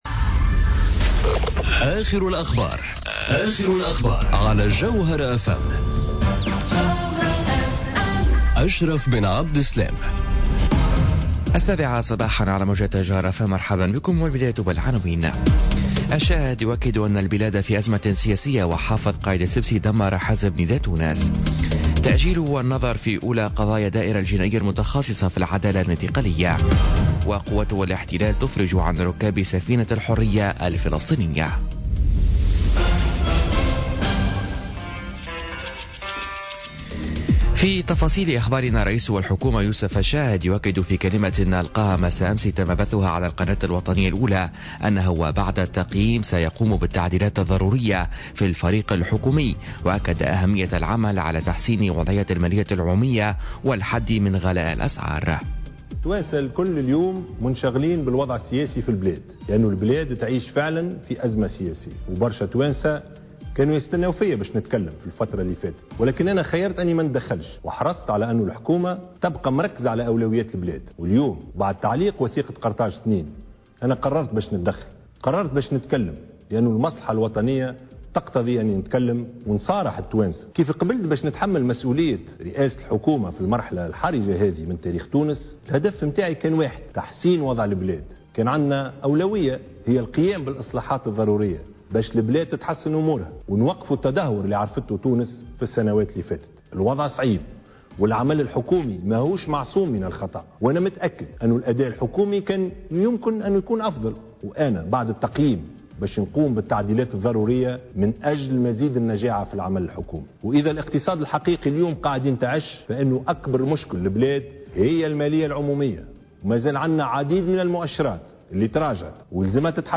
نشرة أخبار السابعة صباحا ليوم الإربعاء 30 ماي 2018